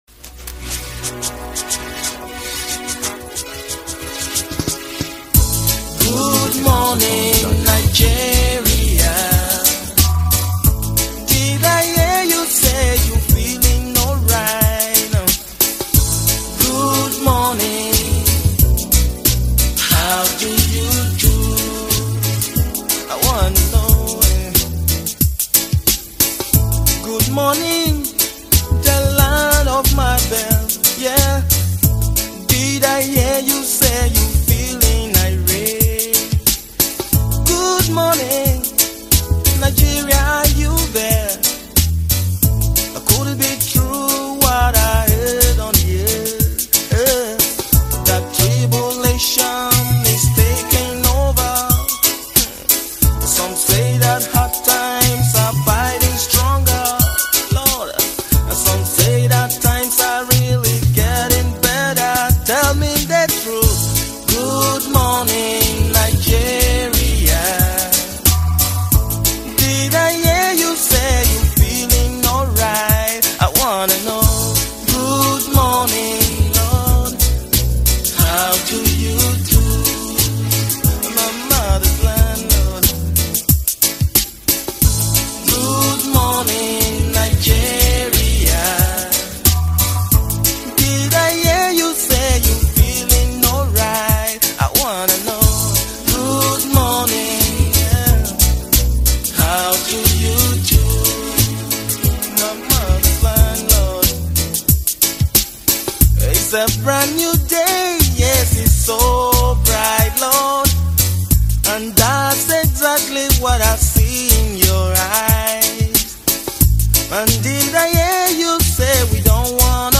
Tiv songs
encouraging, uplifts the spirit and soul